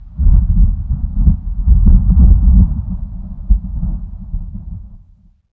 thunder21.ogg